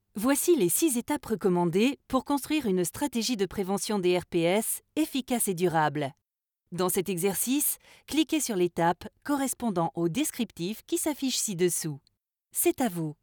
Ma voix peut être chaleureuse, explicative, douce, dynamique, sensuelle ou enjouée.
Sprechprobe: eLearning (Muttersprache):
I have an explaning, soft, dynamic, warm or sensual voice.